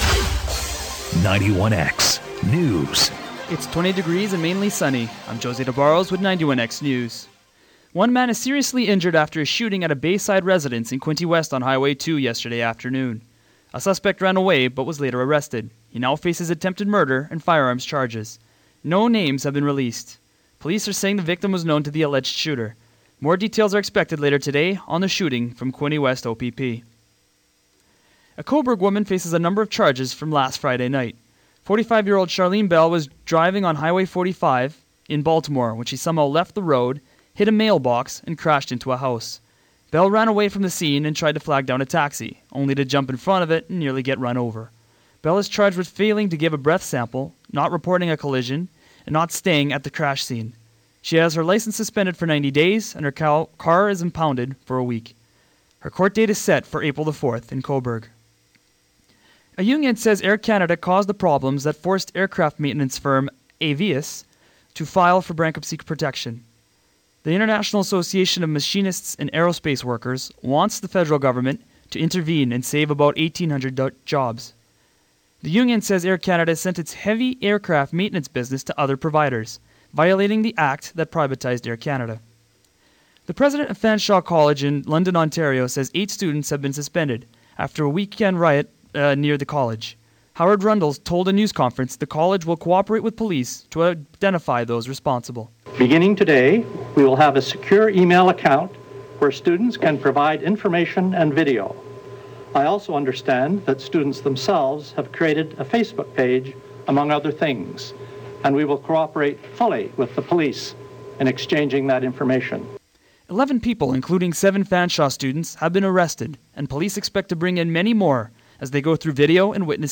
91X News monday march 19 2012